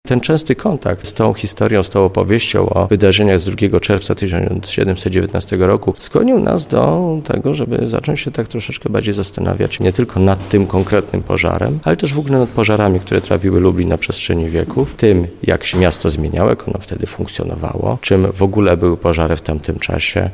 Wszystko zaczęło się od obrazu „Pożar Miasta Lublina” z 1719 roku – tłumaczy